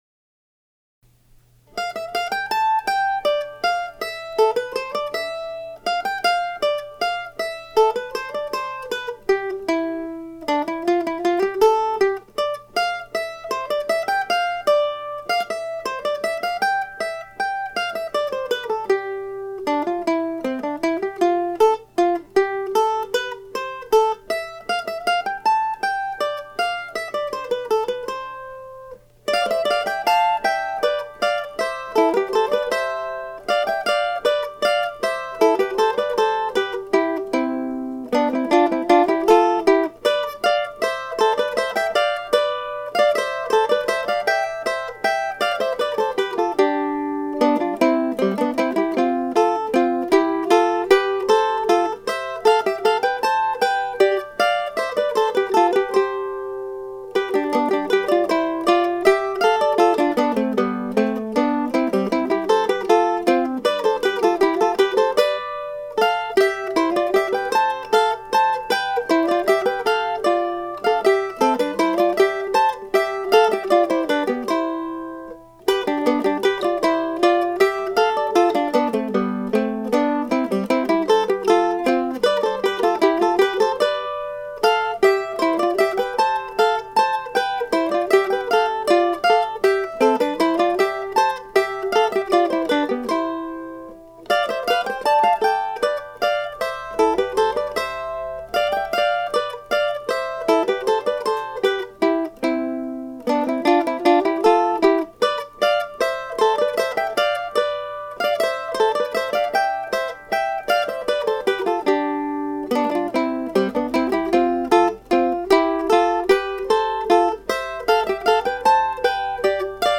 No. 4, presented here, goes a bit faster than the tempo indicated in the sheet music (although I have corrected this in my Midwestern Mandolin Duos book) and I played a few unintended notes at the start of the final A section.